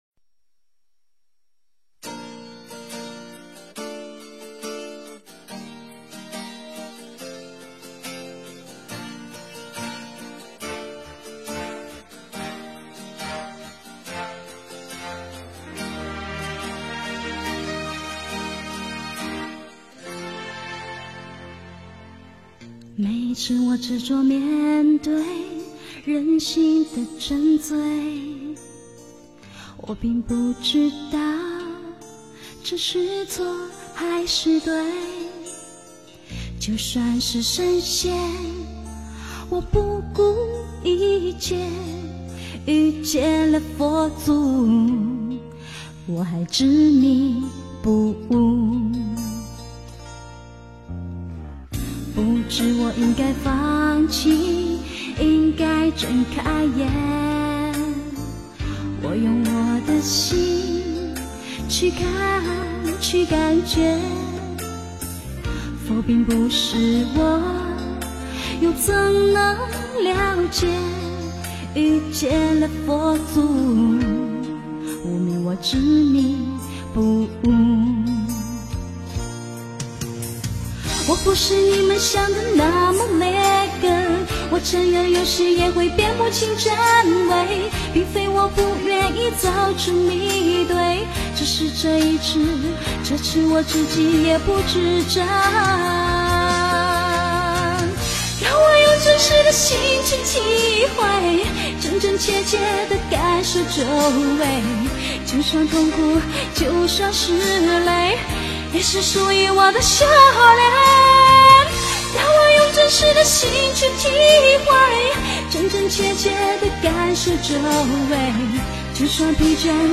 佛教音乐